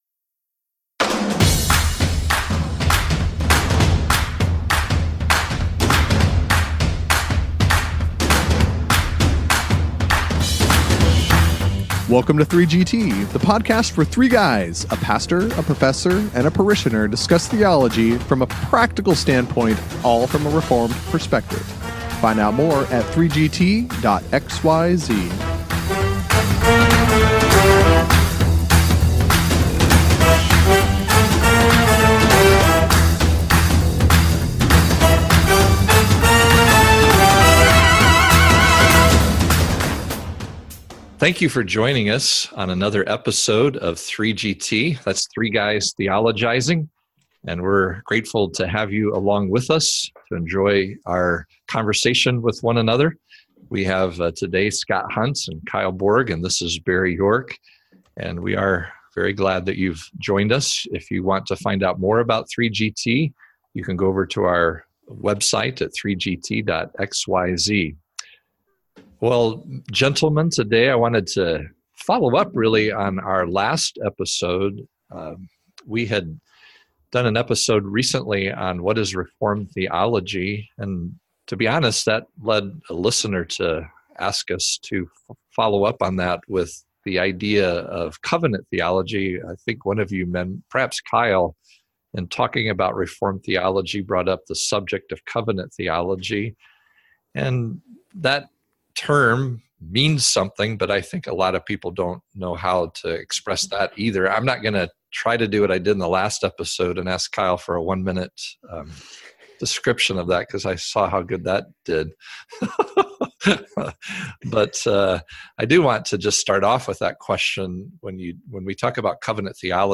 After a conversation on what Reformed means, the guys follow up with a discussion on covenant theology.
Hold on to your hats, for it’s another rollicking discussion on 3GT!